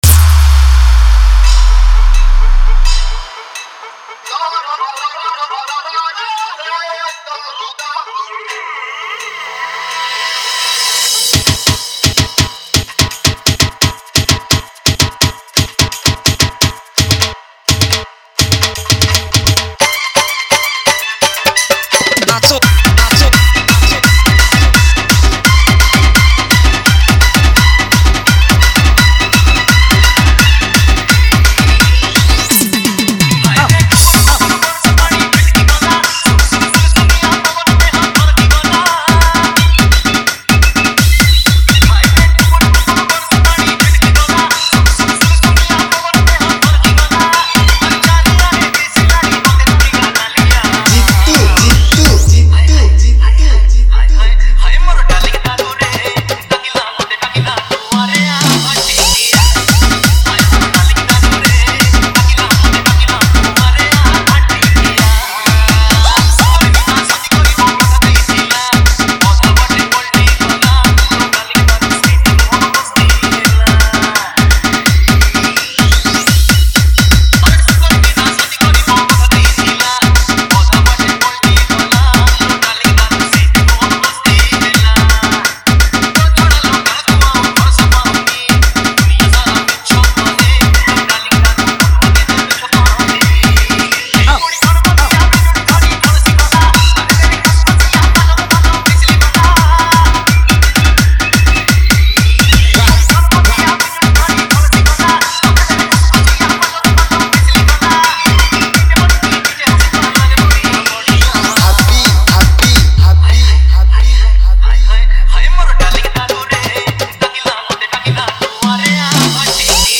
Category:  Odia Old Dj Song